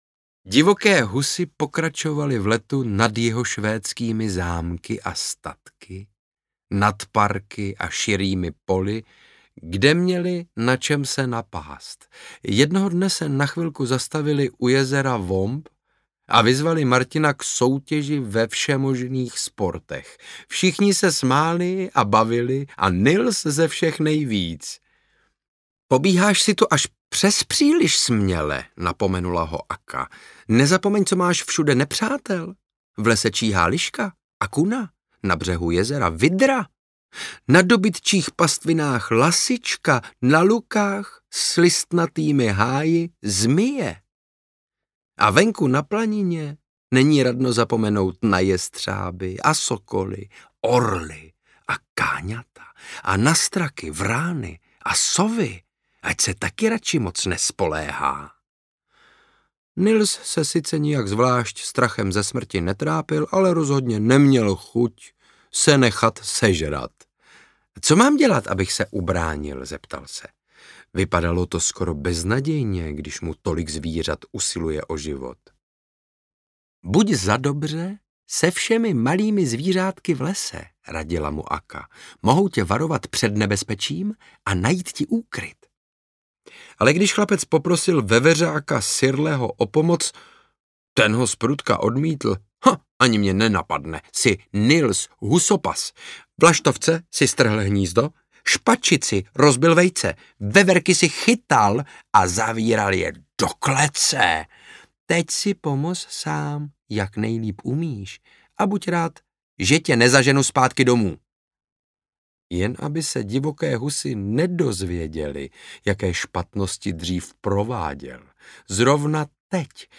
Podivuhodná cesta Nilse Holgerssona Švédskem audiokniha
Ukázka z knihy
• InterpretSaša Rašilov